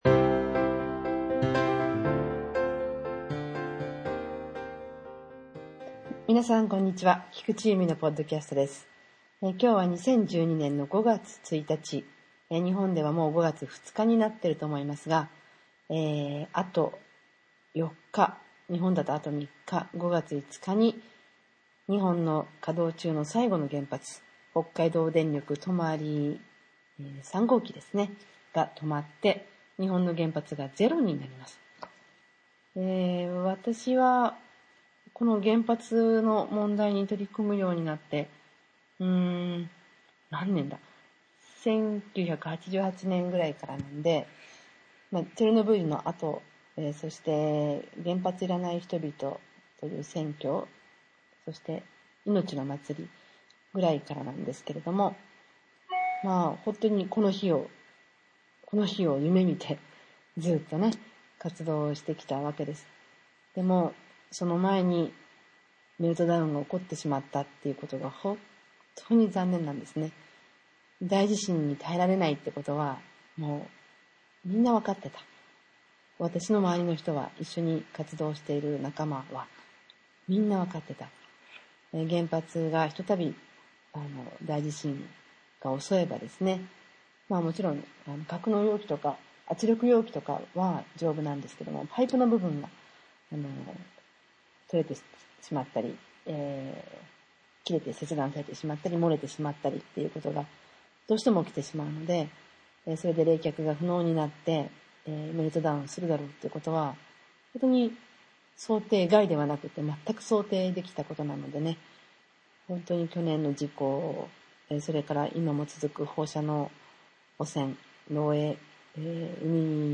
再録したのは私だけです）。